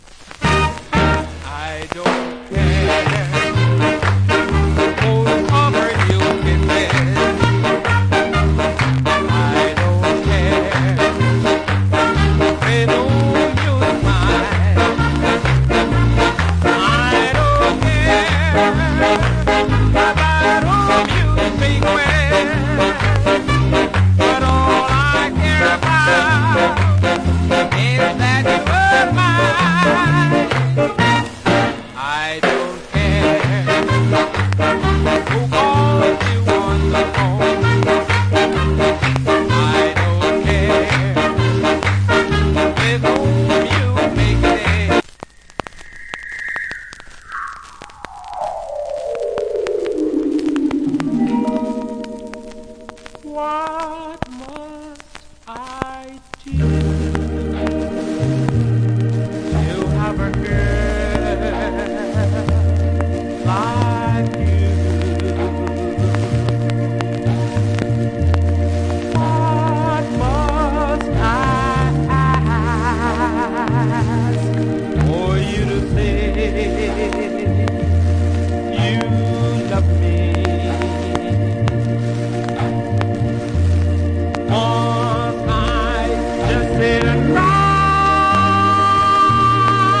Top Ska Vocal.